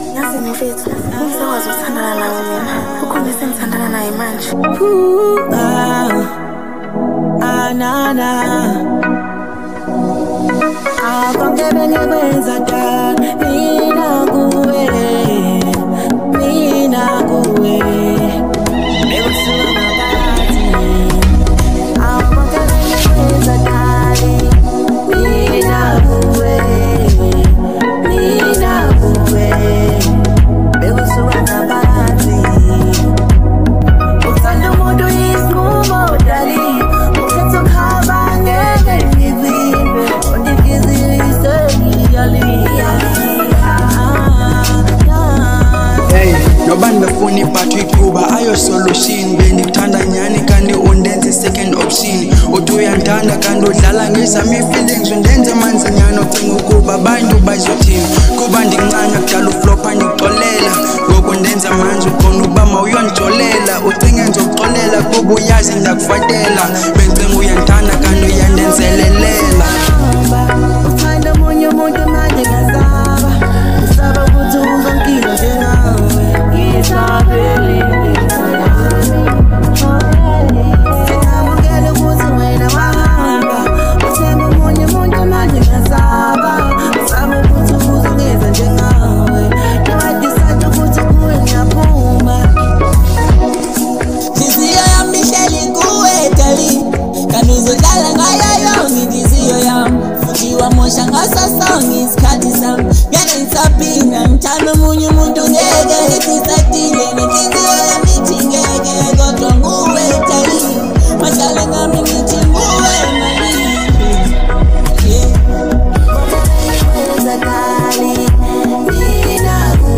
Genre : Afro Pop